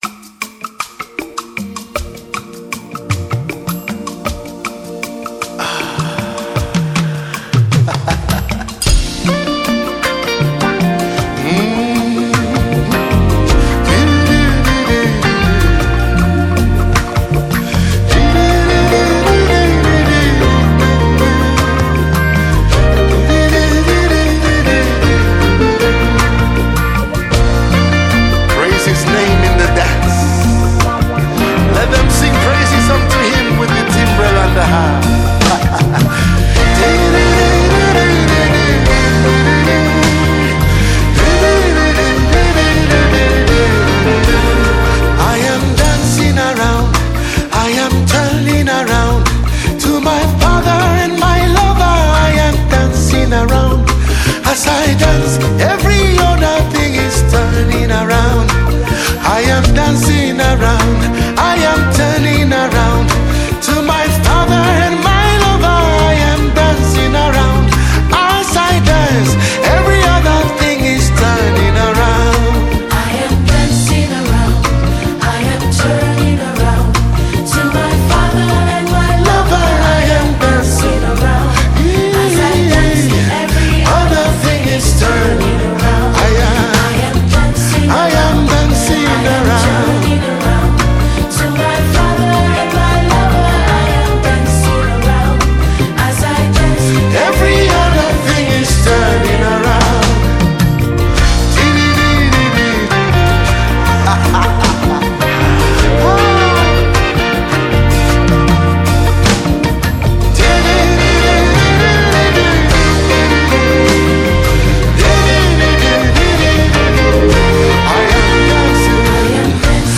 gospel
trumpet